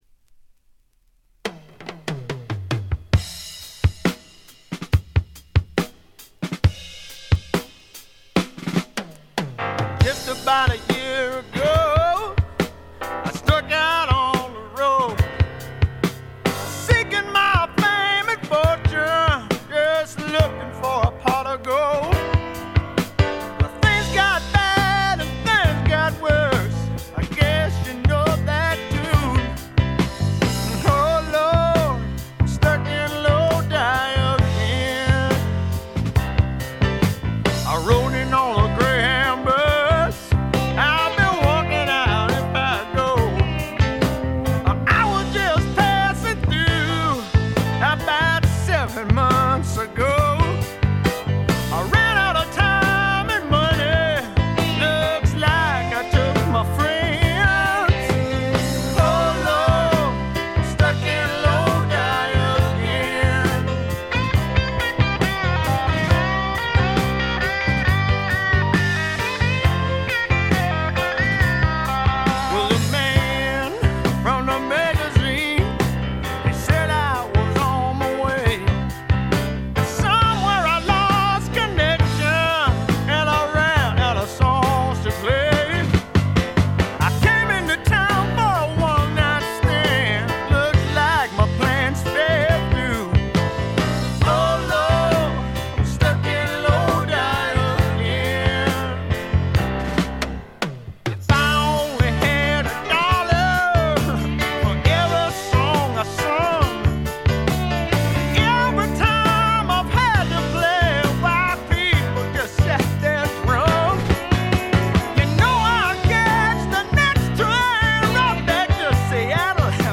ホーム > レコード：米国 スワンプ
部分試聴ですが、微細なノイズ感のみ。
試聴曲は現品からの取り込み音源です。